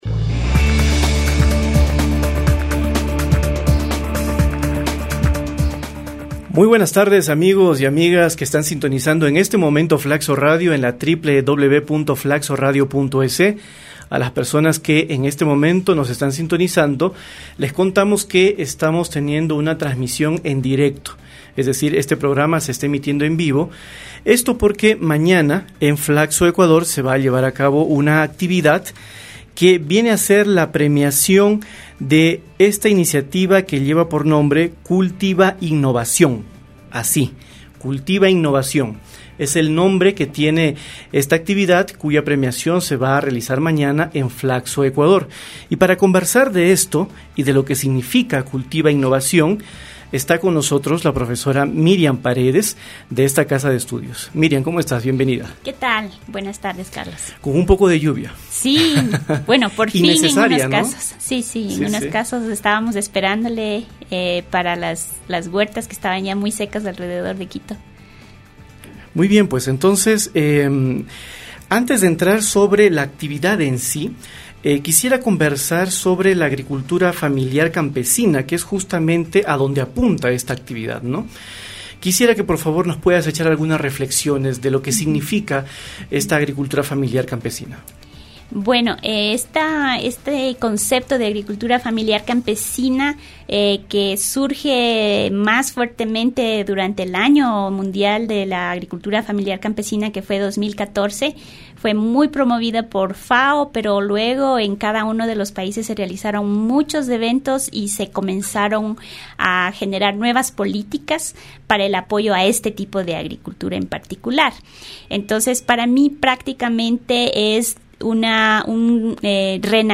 estuvimos en los estudios de FLACSO Radio para conversar sobre la agricultura familiar campesina, a propósito del concurso que llevó el nombre CULTIVAINNOVACION.